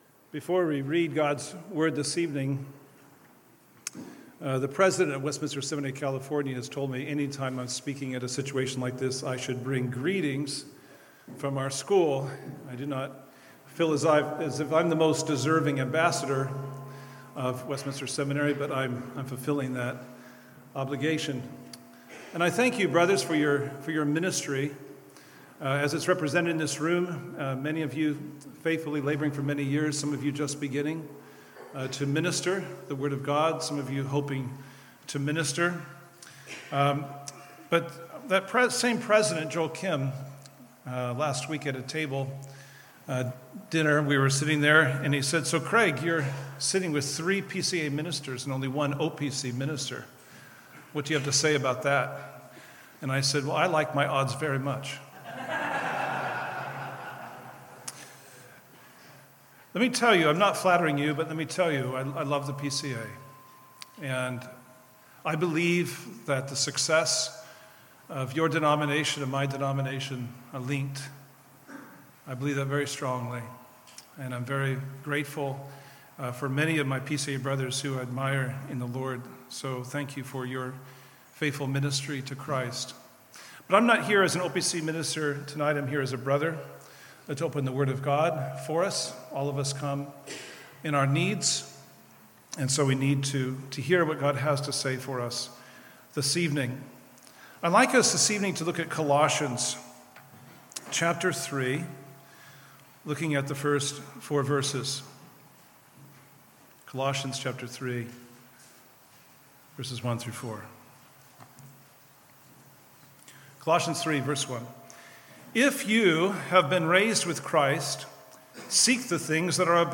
Ministry in Light of Eternity: Worship Service 1 – Our Heavenly Mindset